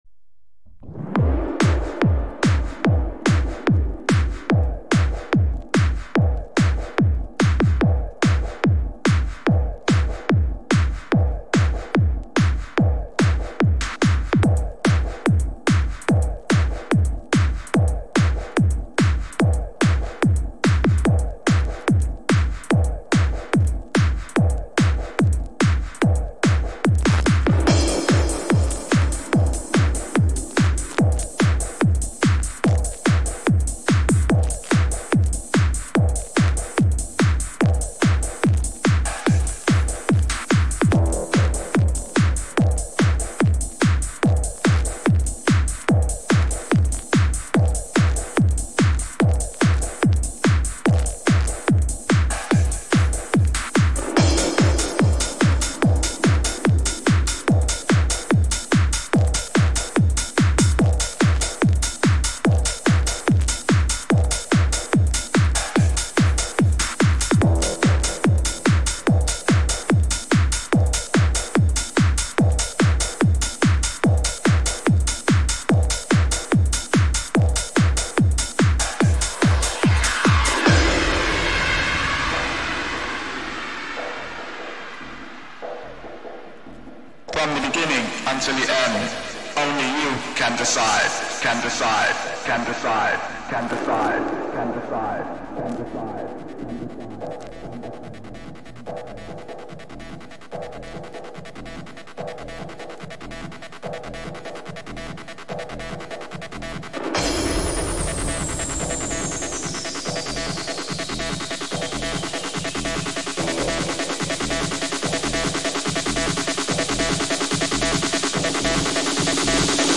Random Hour of Trance
Trance Vocals Rave